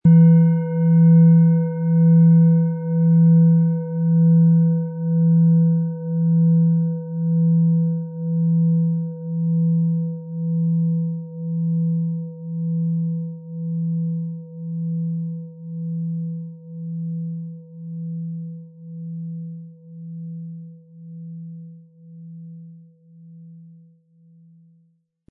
Planetenschale® Freudig sein & Geborgen fühlen mit Hopi-Herzton & Mond, Ø 23 cm, 1300-1400 Gramm inkl. Klöppel
• Tiefster Ton: Mond
• Höchster Ton: Eros
PlanetentöneHopi Herzton & Mond & Eros (Höchster Ton)
MaterialBronze